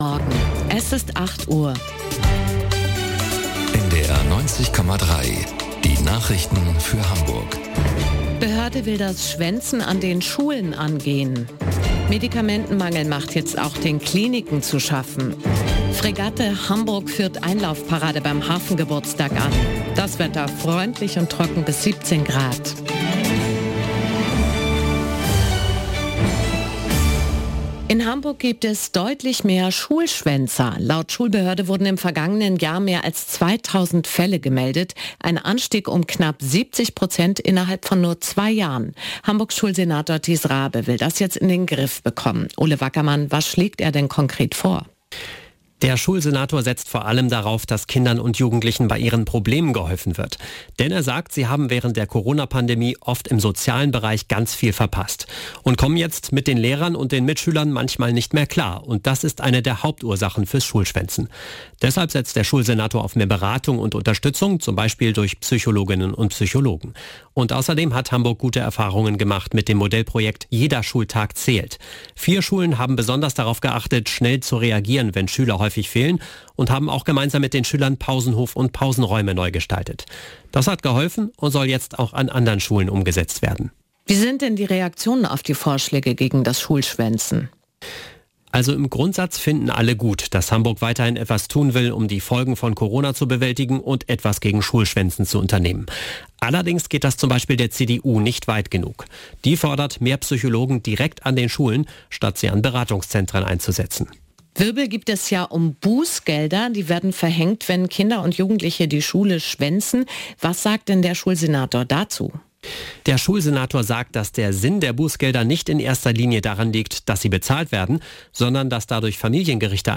Nachrichten - 27.07.2023